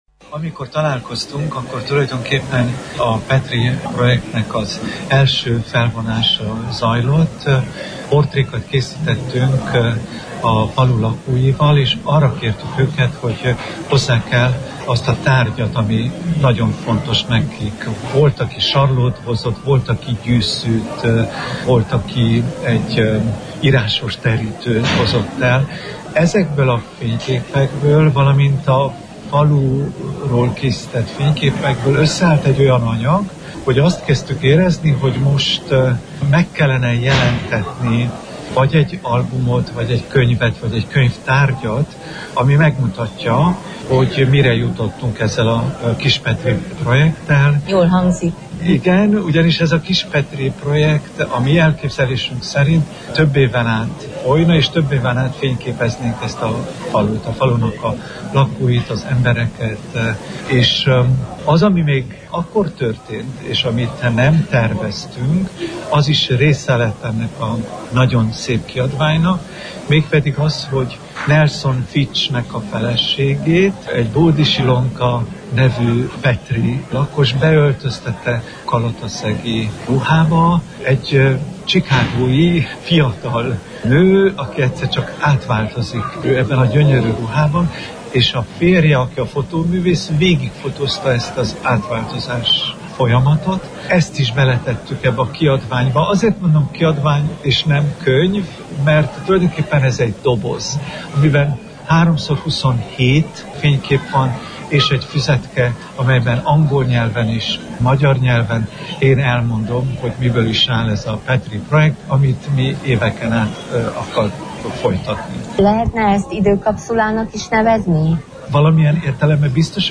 a marosvásárhelyi könyvbemutató után